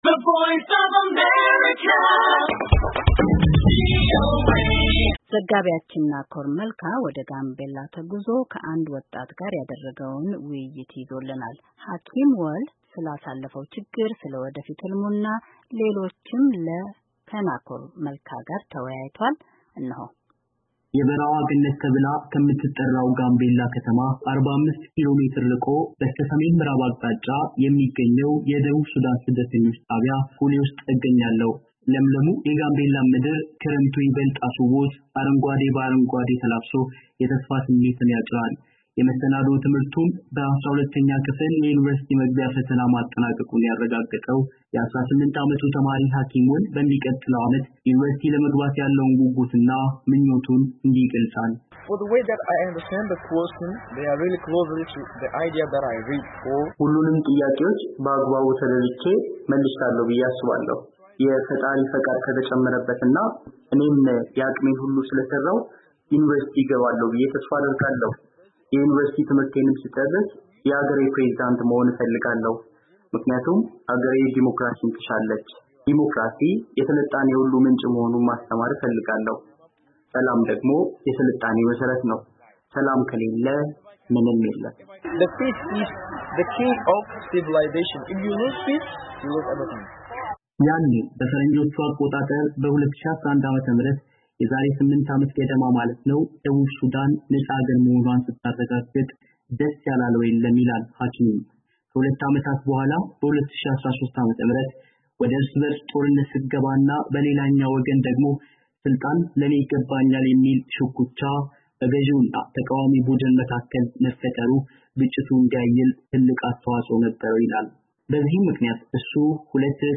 በጋምቤላ ተገኝቶ ከአንድ ወጣት ጋር ቆይታ አድርጓል፡፡